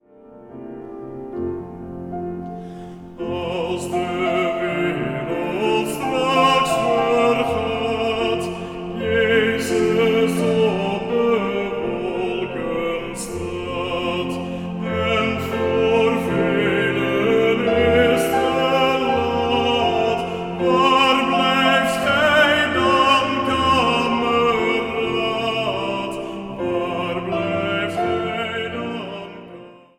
Zang | Solozang